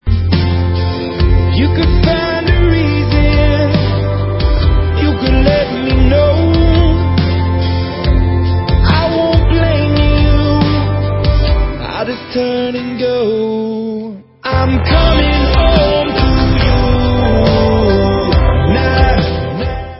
spíše energickými baladami o životě.